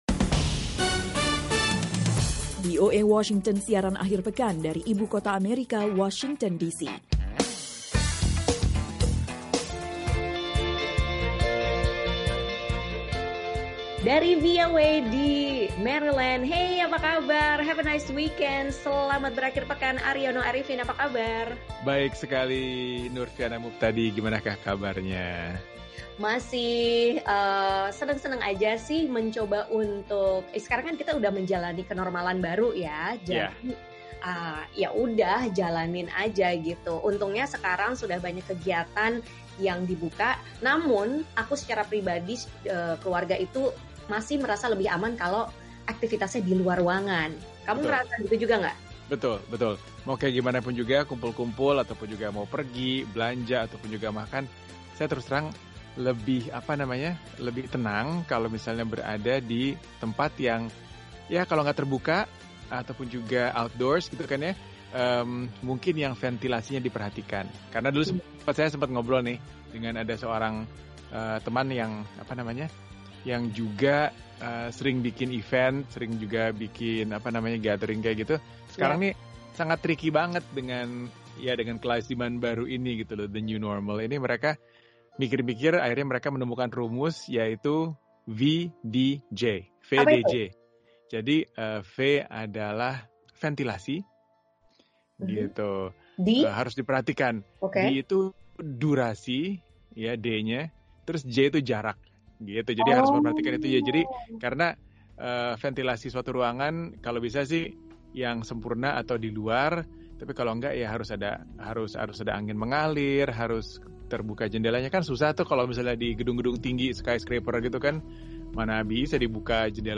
Muslim di Amerika sangat kritis dan Islam bukanlah tamu di Amerika. Pernyataan itu mengemuka dalam diskusi virtual mengenai Islam di AS Dua pembicara dengan pengalaman berbeda tentang Amerika, memaparkan penilaian masing-masing.